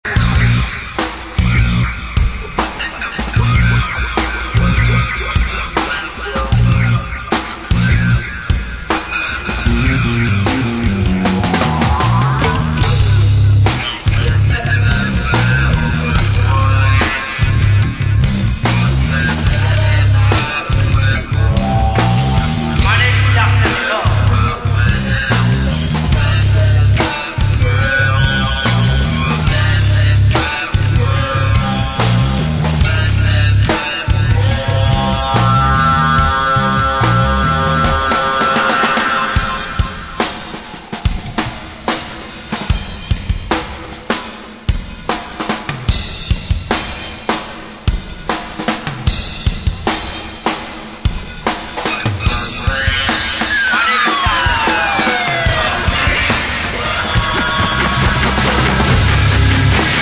hip hop group